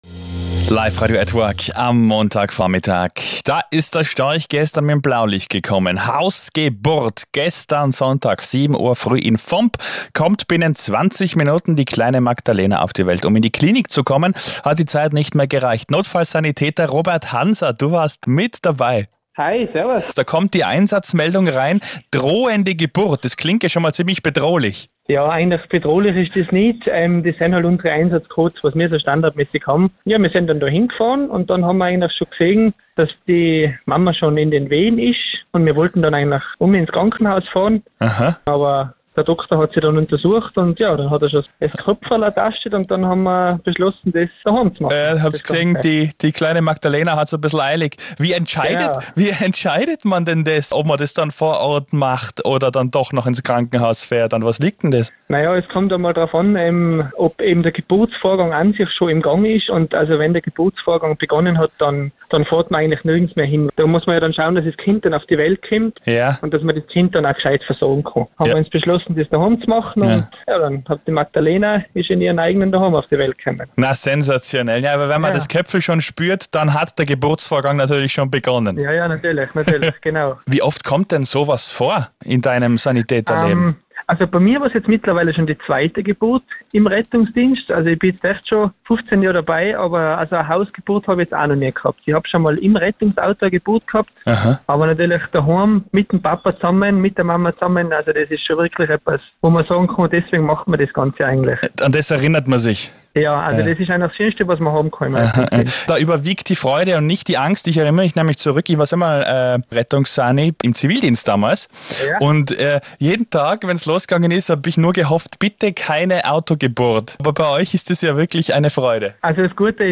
Liferadio-Tirol Interview